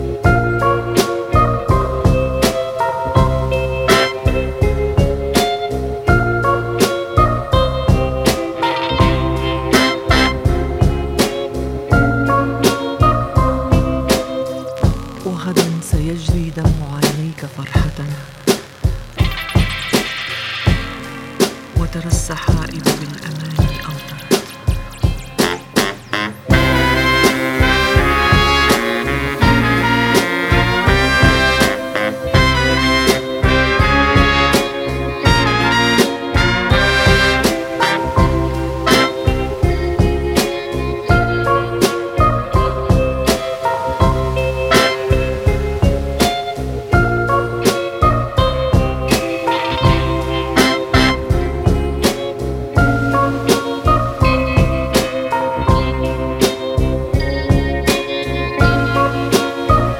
a group known for their Ethio-jazz fusion.